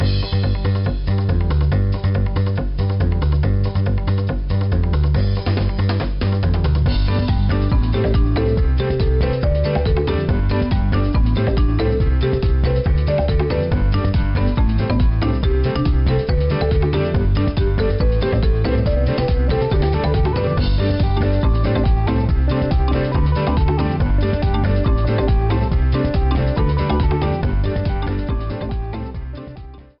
title screen music
Edited Cut at 30s, fadeout of 3s at the end.